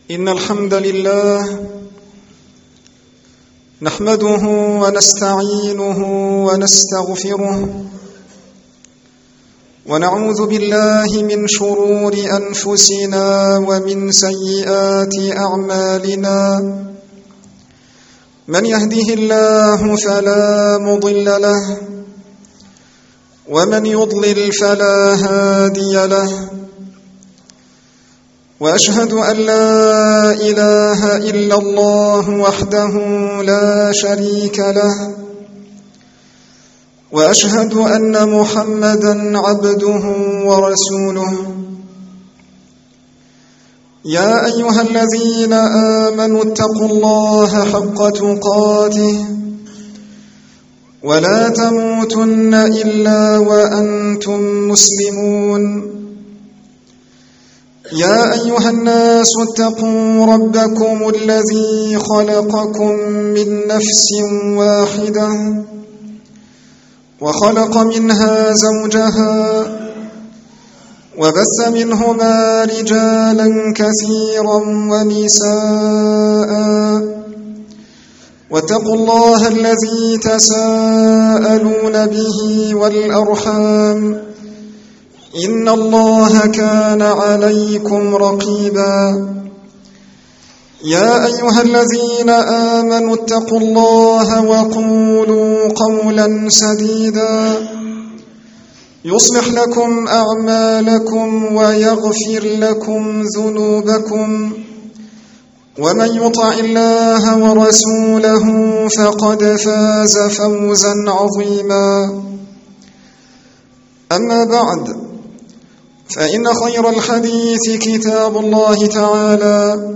خطب عامة